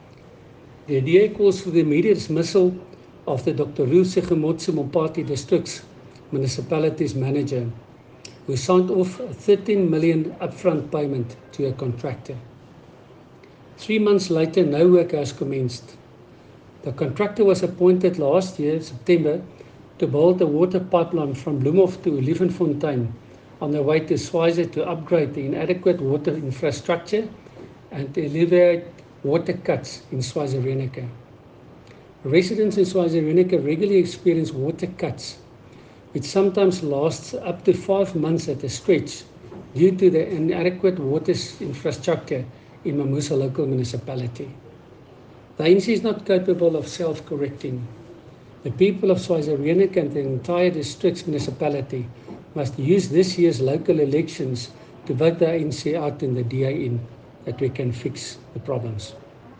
English and Afrikaans by Cllr Coenrad Herbst, DA Councillor in Dr Ruth Segomotsi Mompati District Municipality.
Coenrad-Herbst-English-Voice-Note.mp3